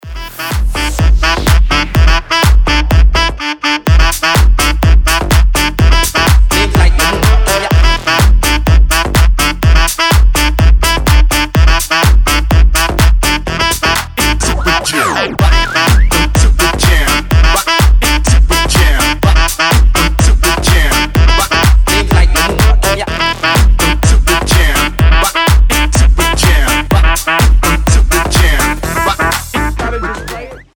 громкие
зажигательные
EDM
Саксофон
Tech House
энергичные
труба
Энергичная музыка для звонка смартфона